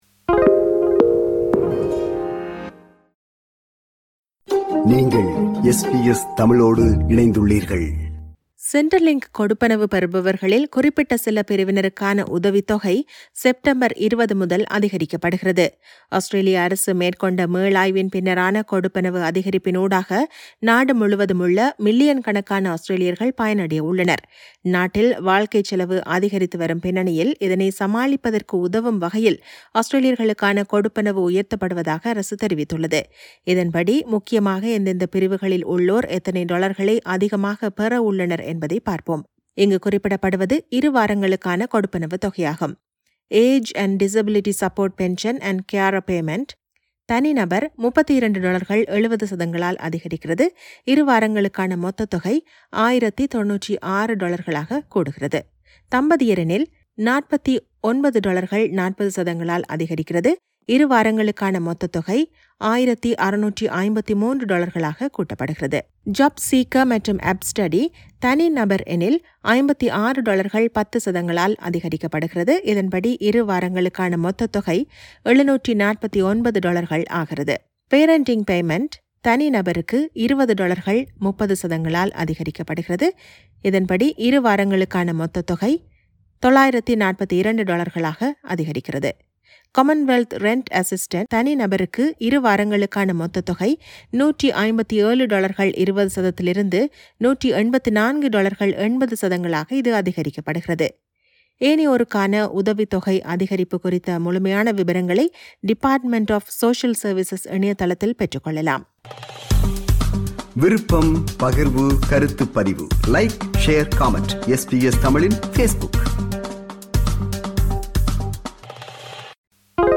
Centrelink கொடுப்பனவு பெறுபவர்களில் குறிப்பிட்ட சில பிரிவினருக்கான உதவித்தொகை, செப்டம்பர் 20 முதல் அதிகரிக்கப்படுகிறது. இதுகுறித்த செய்தி விவரணத்தை